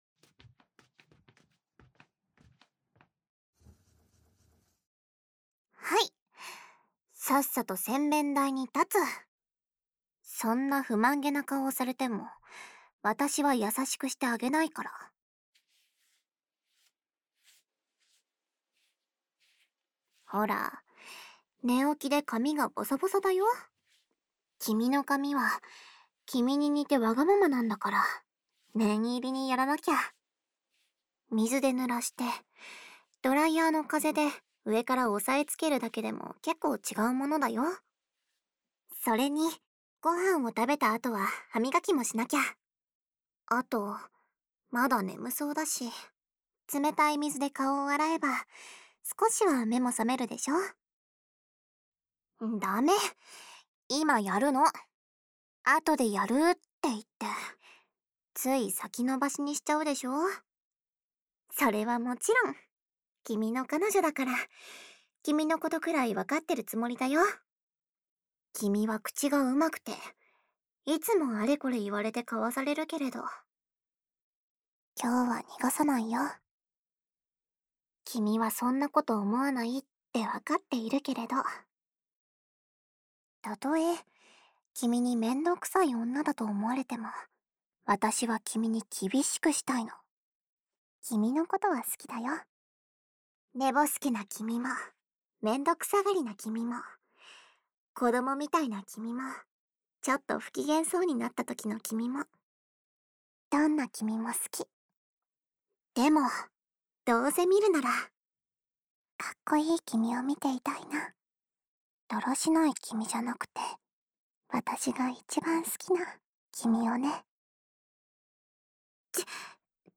纯爱/甜蜜 温馨 治愈 掏耳 环绕音 ASMR 低语
el97_03_『身嗜みには注意だよ』（歯磨き・ドライヤー）.mp3